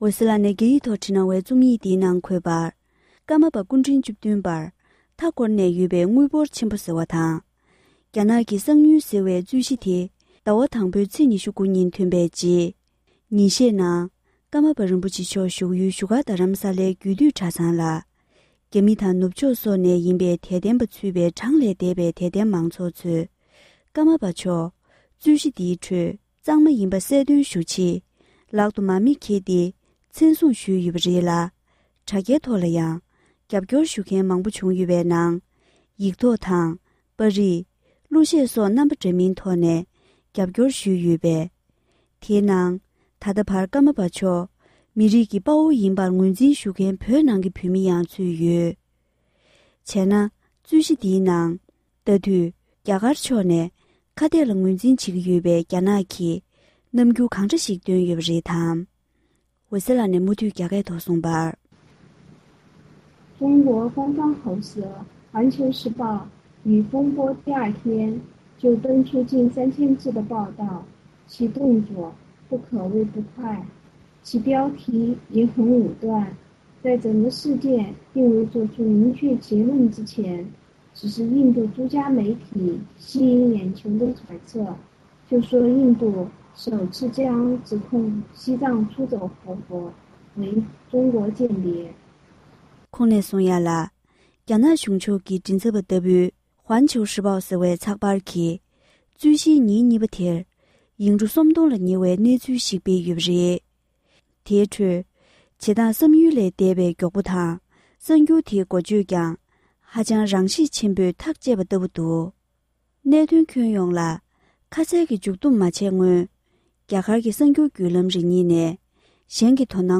བོད་སྐད་ཐོག་ཕབ་བསྒྱུར་གྱིས་སྙན་སྒྲོན་ཞུས་པར་གསན་རོགས༎